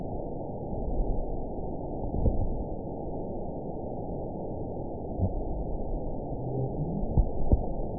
event 920553 date 03/30/24 time 02:15:13 GMT (1 year, 1 month ago) score 6.26 location TSS-AB04 detected by nrw target species NRW annotations +NRW Spectrogram: Frequency (kHz) vs. Time (s) audio not available .wav